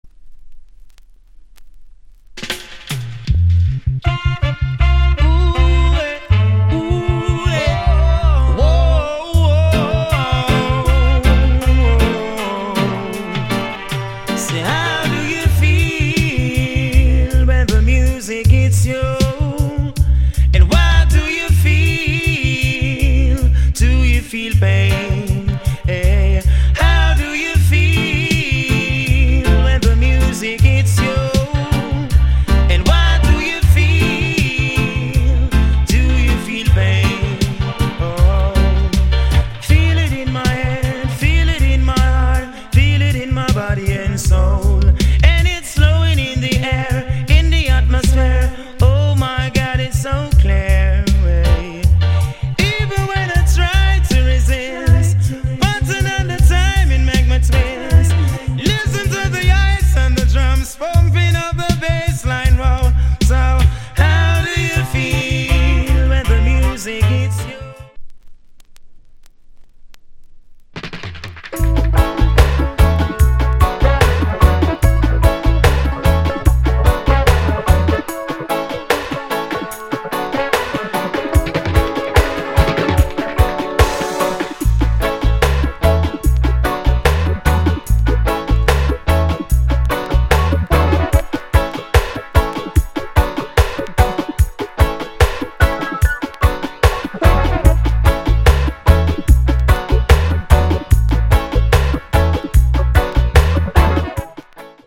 * Wicked Vocal!!